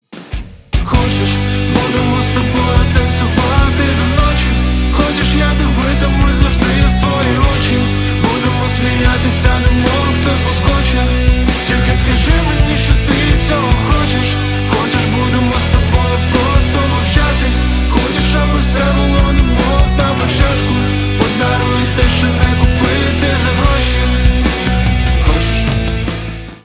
• Качество: 320, Stereo
Rap-rock
Рэп-рок
нежные
романтические